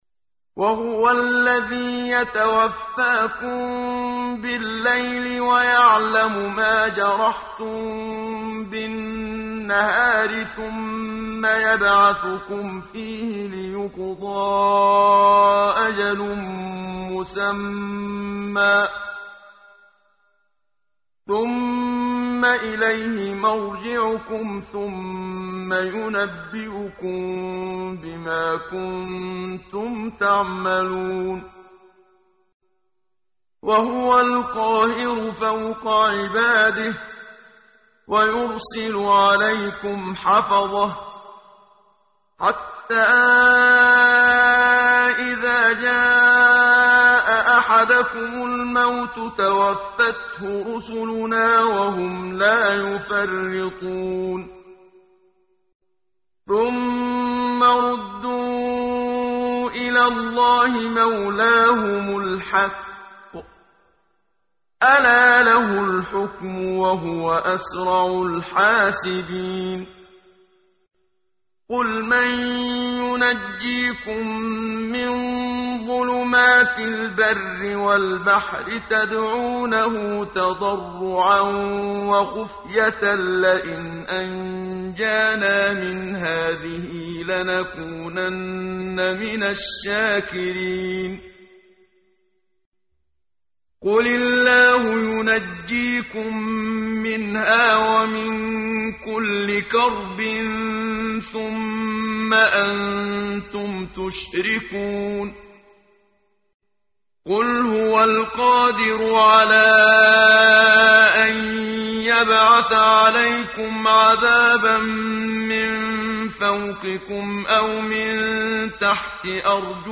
ترتیل صفحه 135 سوره مبارکه انعام (جزء هفتم) از سری مجموعه صفحه ای از نور با صدای استاد محمد صدیق منشاوی